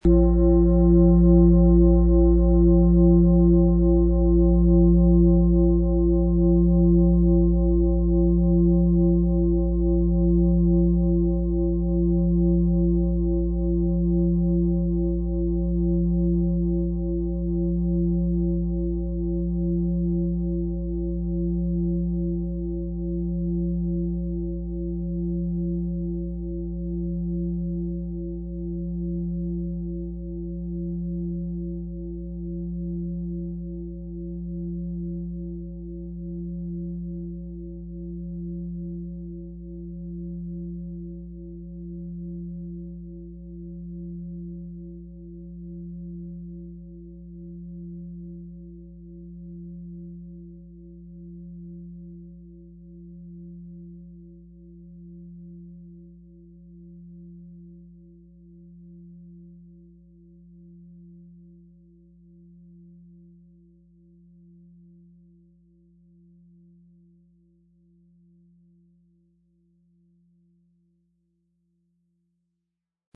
XXXL Planeten-Fußreflexzonen-Klangschale - heiter und befreit begleitet mit Planetenton Platonisches Jahr - bis Schuhgröße 42, Ø 50,5 cm, 10,95 kg, mit Klöppel
Planetenton 1
Unter dem Artikel-Bild finden Sie den Original-Klang dieser Schale im Audio-Player - Jetzt reinhören.